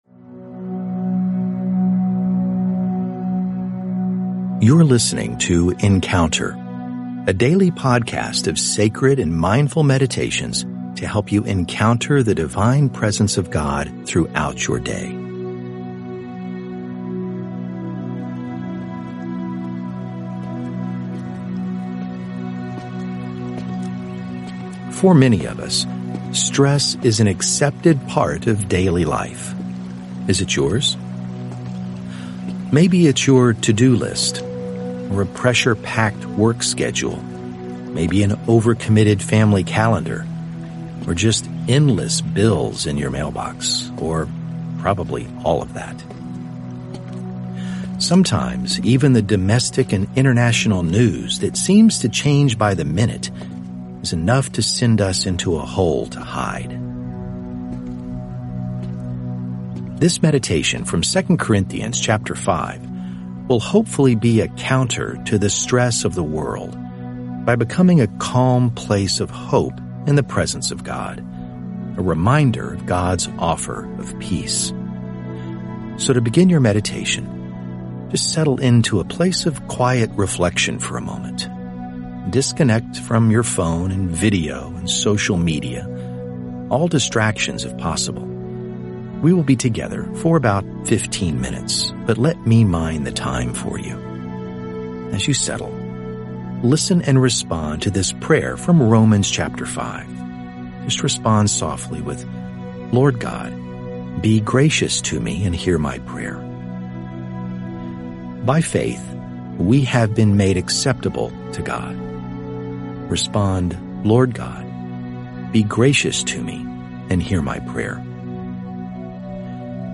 This meditation from 2 Corinthians 5 will hopefully be a counter to the stress of the world by becoming a calm place of hope in the presence of God. A reminder of God’s offer of peace.